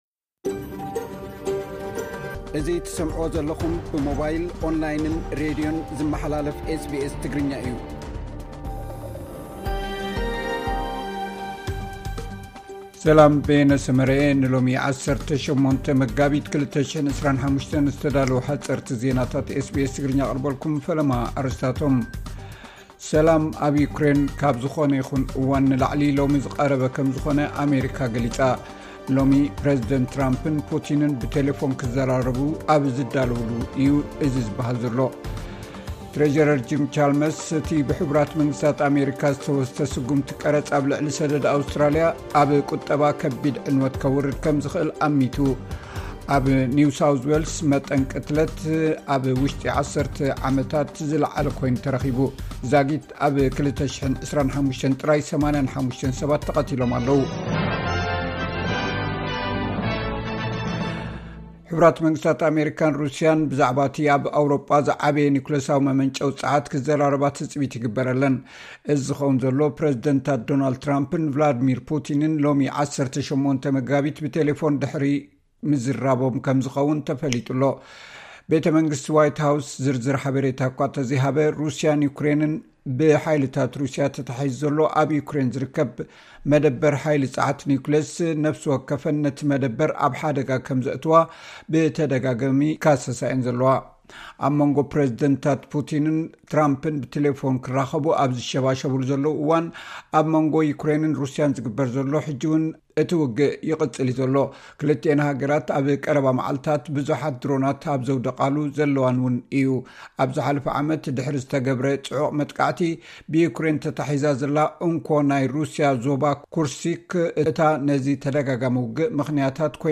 ሓጸርቲ ዜናታት ኤስ ቢ ኤስ ትግርኛ (18 መጋቢት 2025)